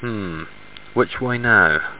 home *** CD-ROM | disk | FTP | other *** search / Horror Sensation / HORROR.iso / sounds / iff / which.snd ( .mp3 ) < prev next > Amiga 8-bit Sampled Voice | 1992-09-02 | 18KB | 1 channel | 9,016 sample rate | 2 seconds